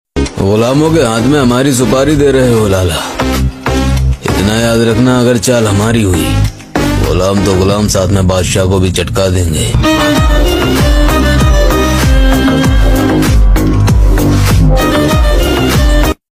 testing Oil pressure at GUDDU sound effects free download